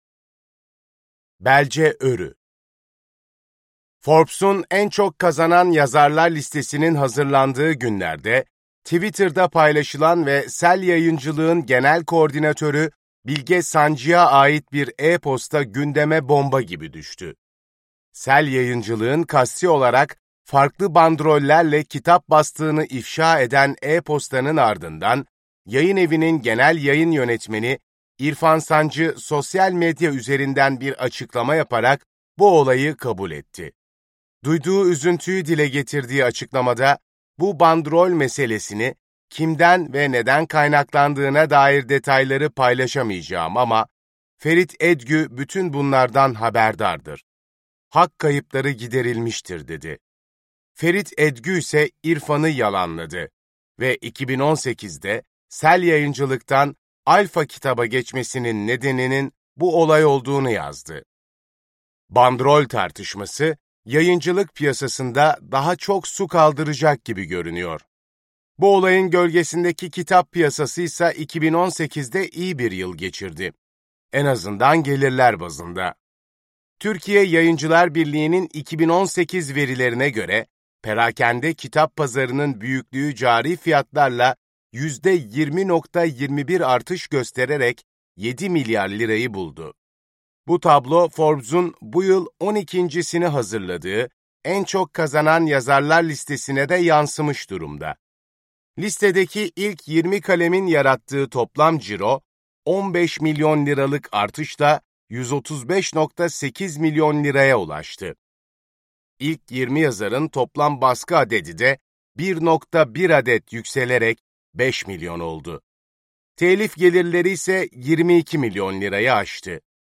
Forbes Mayıs '19 - En Çok Kazanan Yazarlar 2019 - Seslenen Kitap